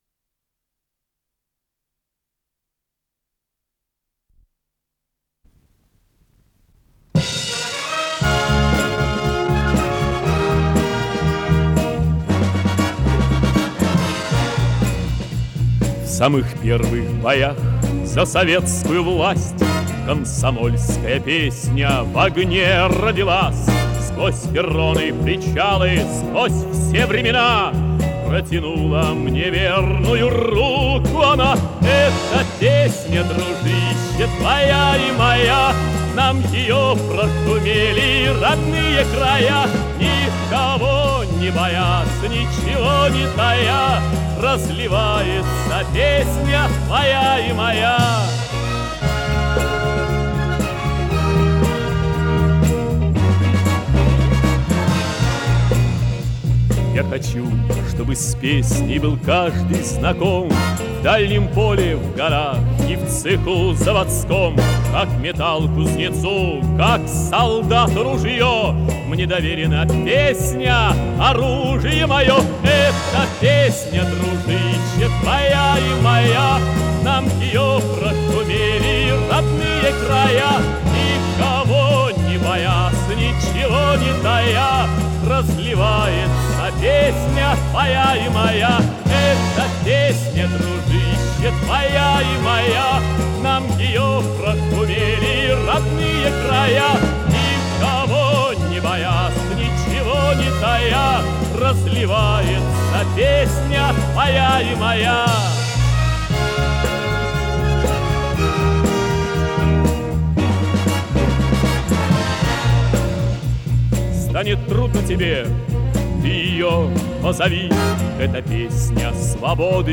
с профессиональной магнитной ленты
баритон
ВариантДубль моно